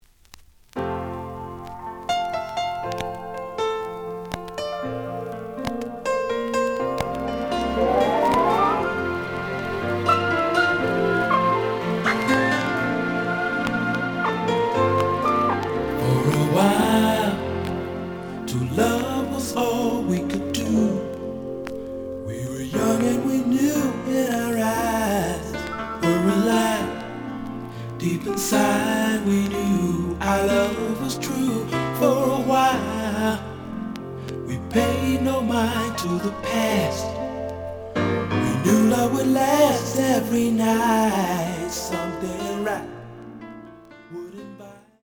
The audio sample is recorded from the actual item.
●Genre: Disco
Some noise on beginning of A side due to scratches.